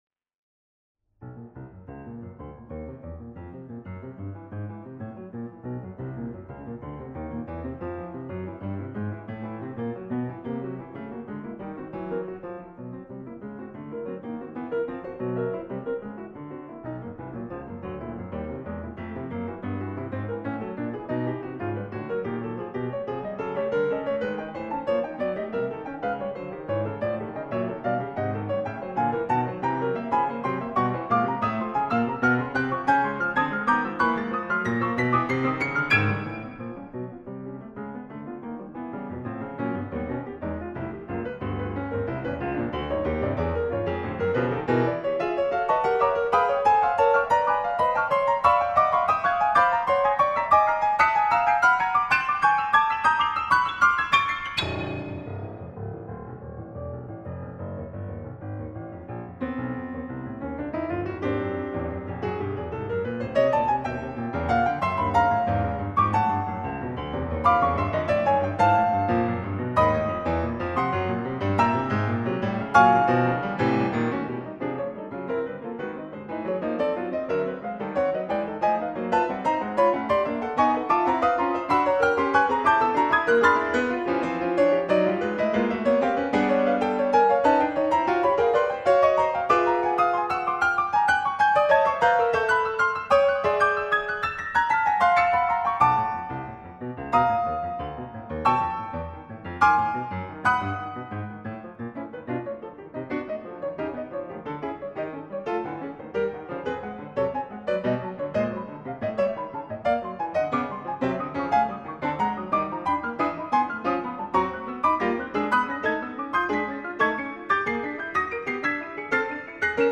Klangbeispiele (Solo & Kammermusik)
Klavier